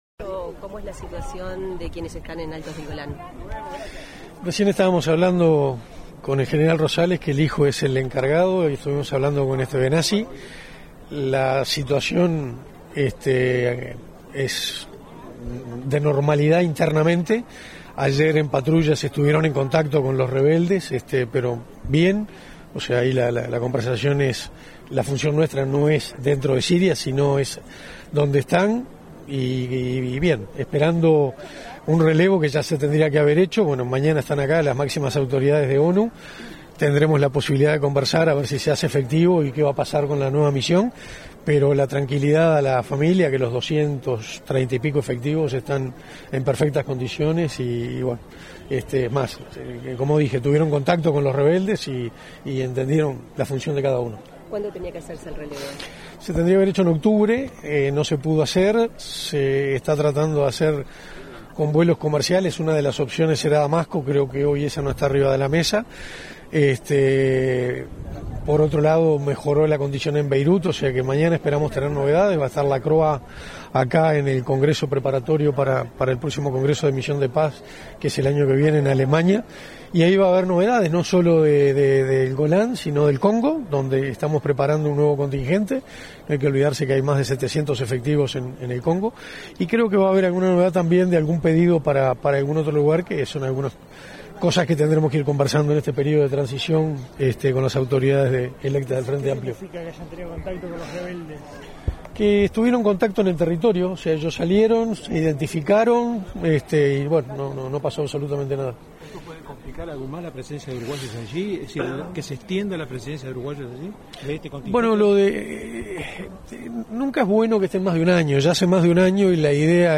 Declaraciones del ministro de Defensa, Armando Castaingdebat, en la Escuela Naval
Declaraciones del ministro de Defensa, Armando Castaingdebat, en la Escuela Naval 09/12/2024 Compartir Facebook X Copiar enlace WhatsApp LinkedIn Este lunes 9, el ministro de Defensa, Armando Castaingdebat, participó en la ceremonia de clausura de cursos de 2024 y graduación de guardiamarinas de la Armada Nacional, pilotos e ingenieros de la Marina Mercante. Al finalizar el evento, dialogó con la prensa.